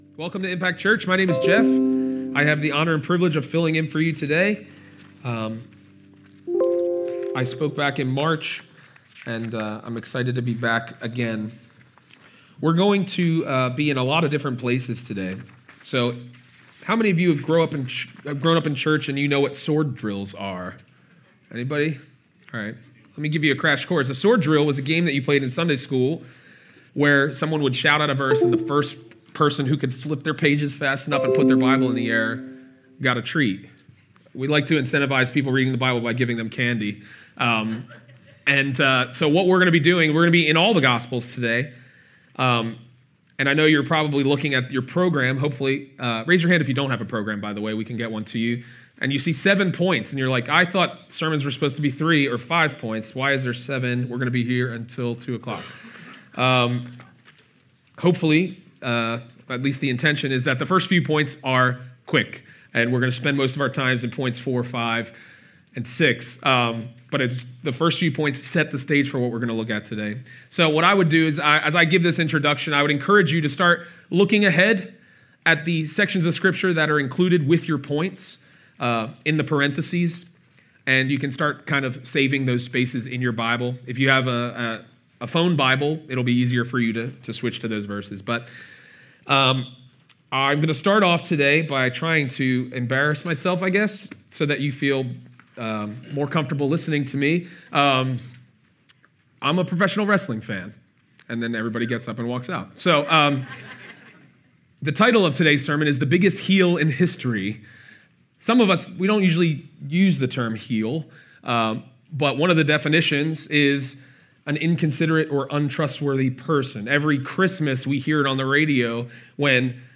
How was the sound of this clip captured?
I was given the opportunity to preach once again at my church. This was the final sermon of a series on the 12 Apostles. This podcast is from the 2nd service.